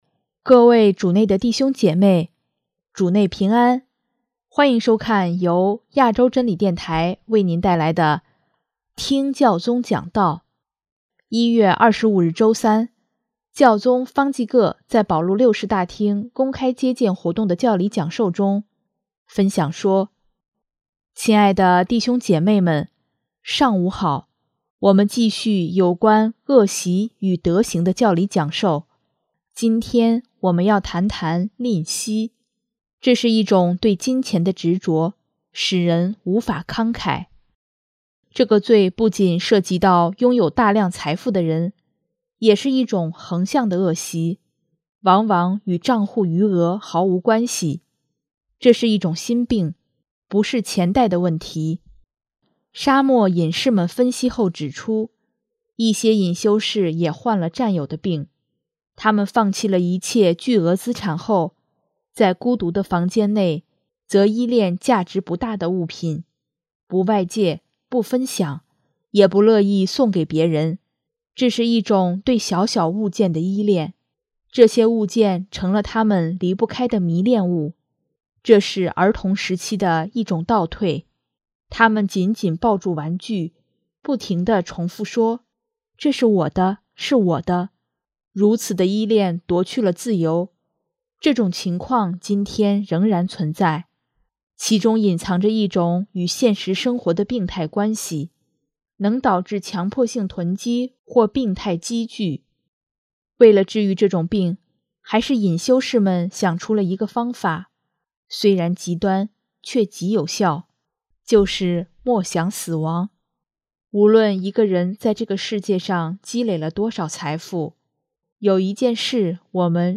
1月25日周三，教宗方济各在保禄六世大厅公开接见活动的教理讲授中，分享说：